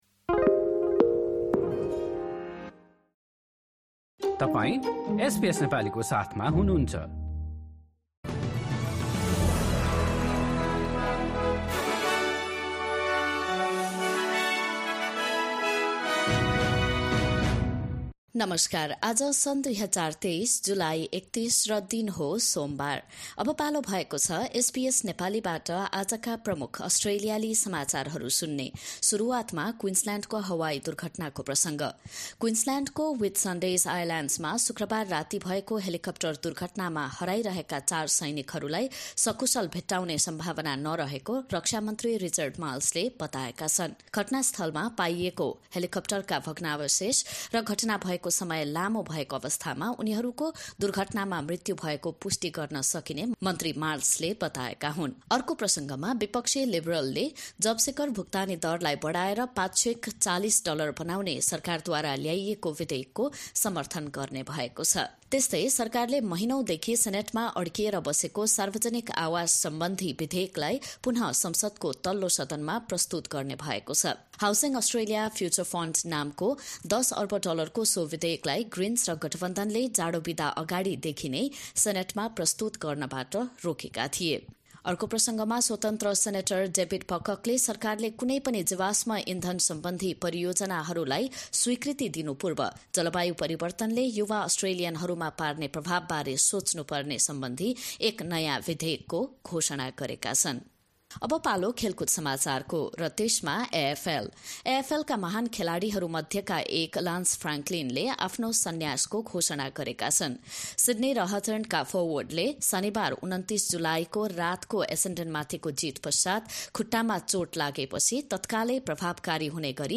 एसबीएस नेपाली प्रमुख अस्ट्रेलियाली समाचार: सोमवार, ३१ जुलाई २०२३